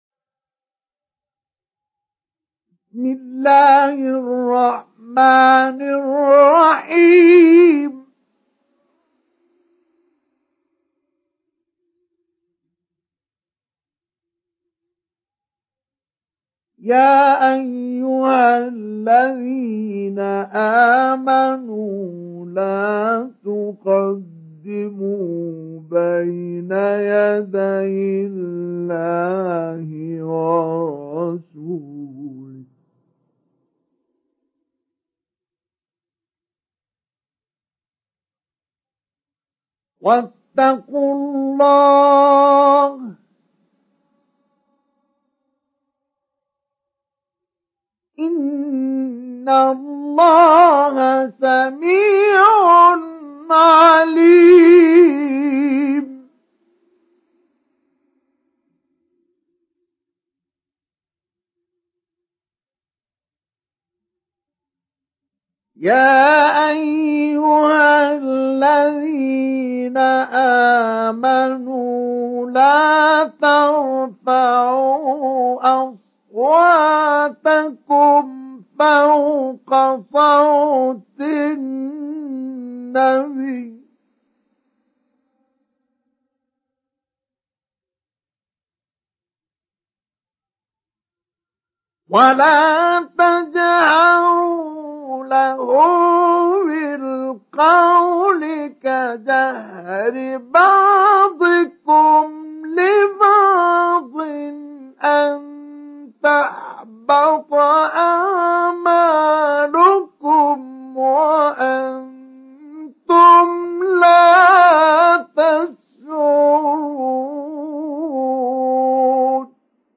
Surat Al Hujurat Download mp3 Mustafa Ismail Mujawwad Riwayat Hafs dari Asim, Download Quran dan mendengarkan mp3 tautan langsung penuh
Download Surat Al Hujurat Mustafa Ismail Mujawwad